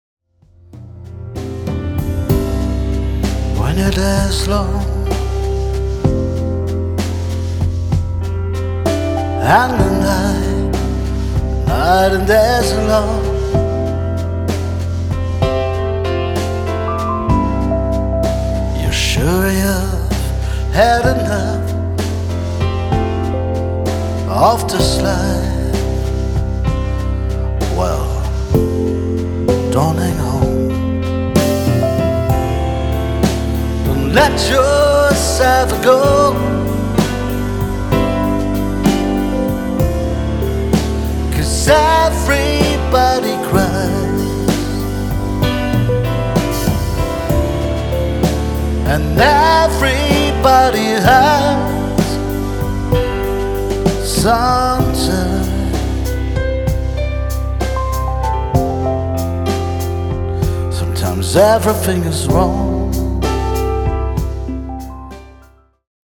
Demoaufnahmen